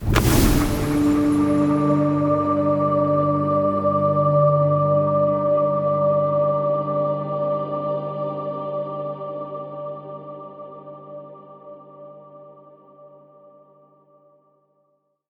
RellVanishBurst.ogg